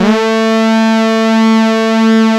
Index of /90_sSampleCDs/Sound & Vision - Gigapack I CD 2 (Roland)/SYN_ANALOG 1/SYN_Analog 2